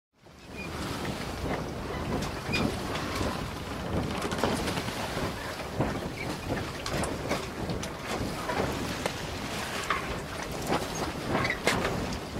boat.mp3